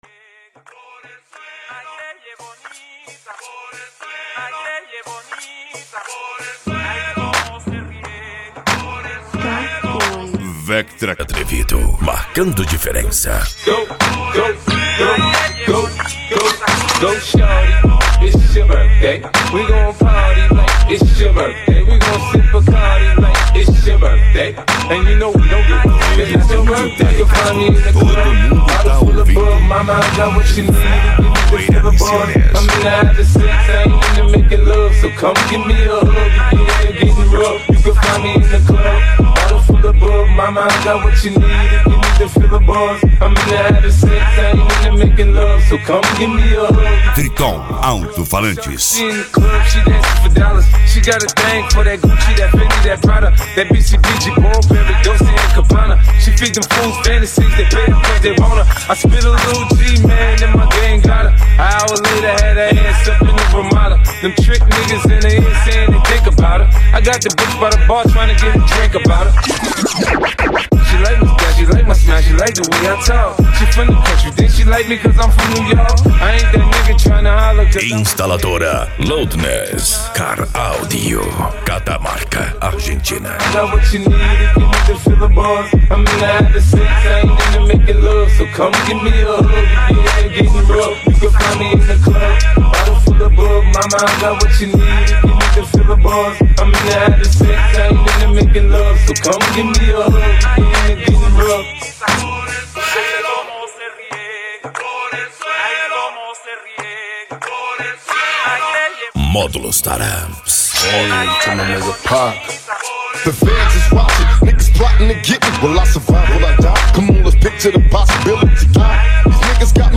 Bass
Cumbia
Musica Electronica
Hip Hop
Remix